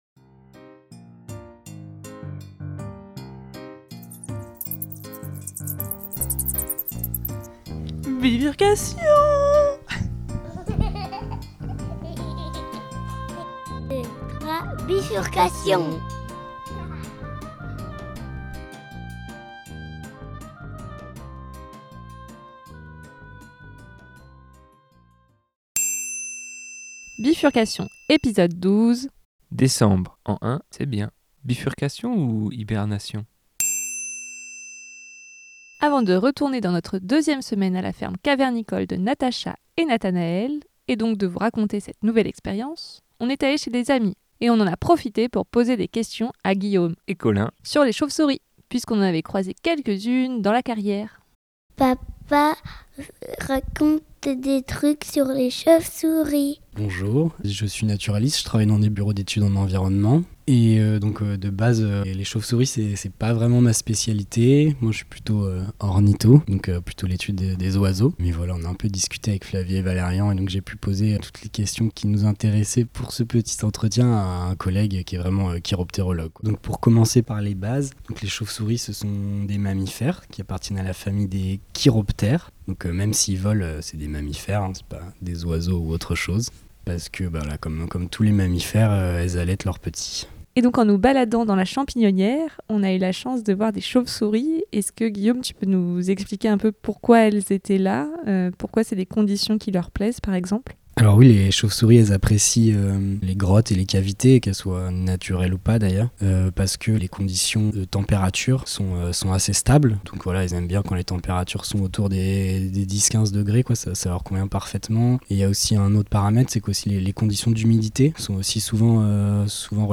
échanges avec un naturaliste (et ami)